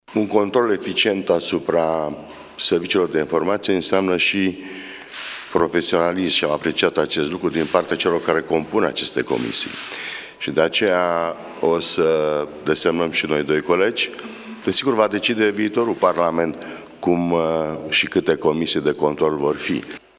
La rândul său, copreședintele PNL Vasile Blaga consideră că este necesar un control eficient asupra activității serviciilor de informații: